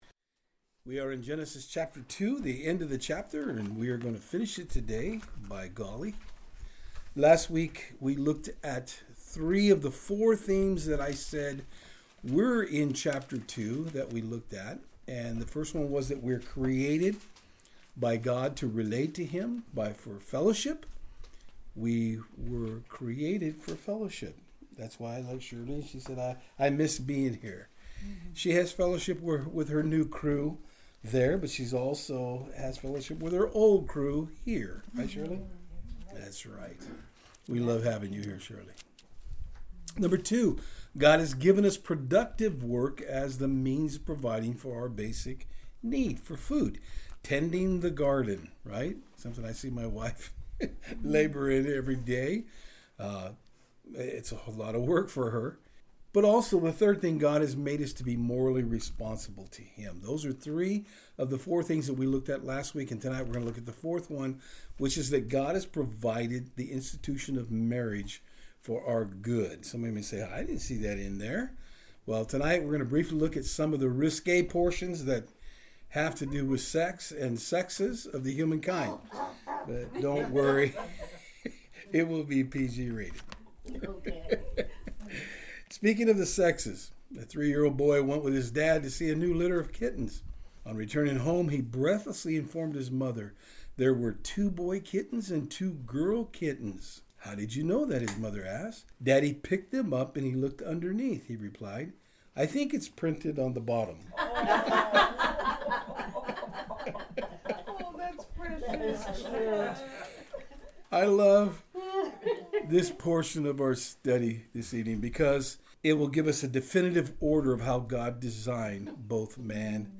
Genesis 2:18-25 Service Type: Saturdays on Fort Hill In our study tonight we will see how God created the woman.